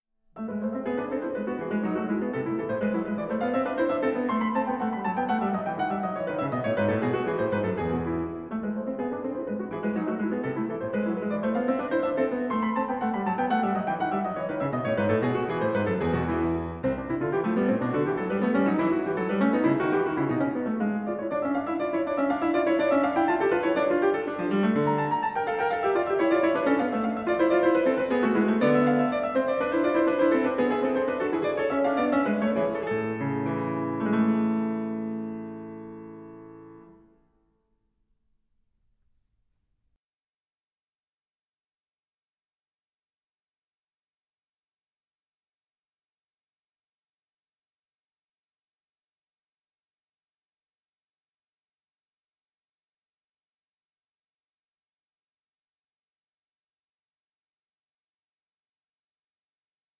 pianist